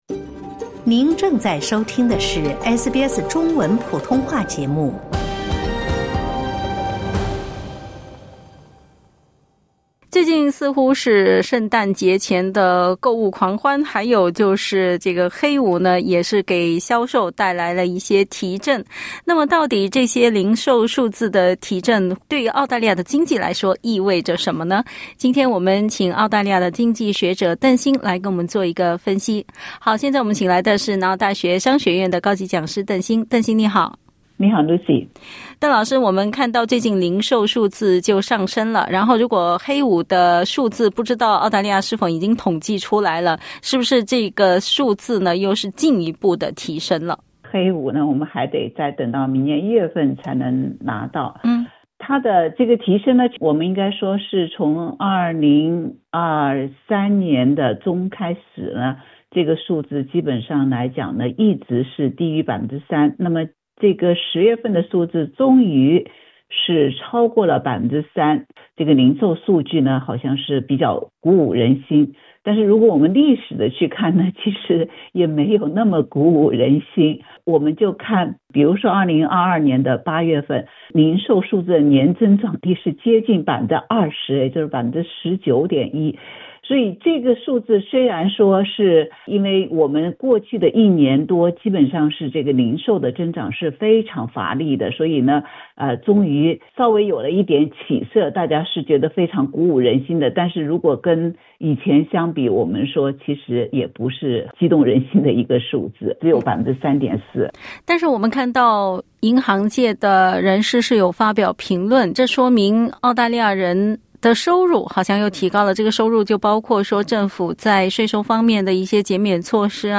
（点击音频收听详细采访） 澳大利亚统计局的最新数据显示，廉价商品的购买者和希望提前打折的零售商推动了澳大利亚消费者支出的增长。